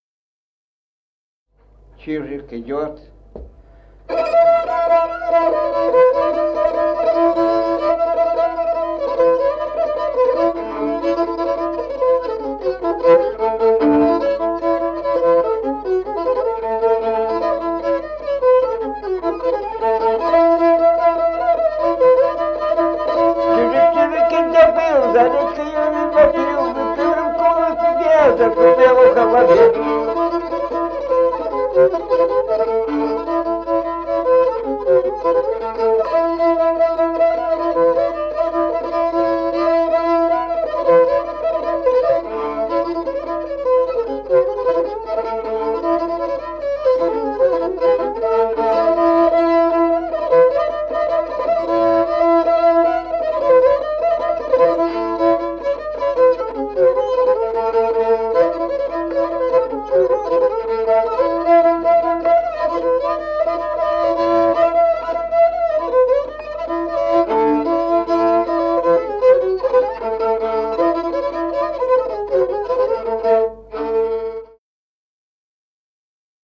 Музыкальный фольклор села Мишковка «Чижик», репертуар скрипача.